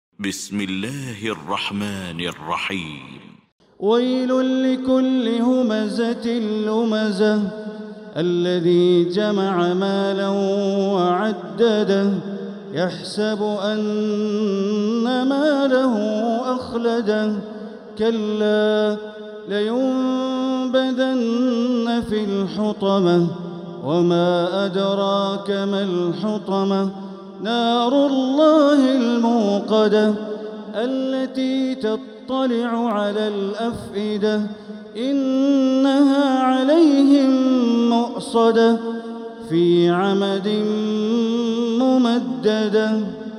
المكان: المسجد الحرام الشيخ: معالي الشيخ أ.د. بندر بليلة معالي الشيخ أ.د. بندر بليلة الهمزة